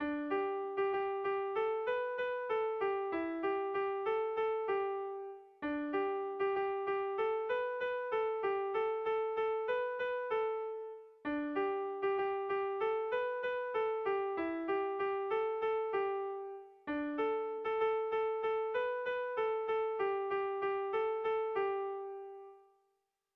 Kontakizunezkoa
AAAA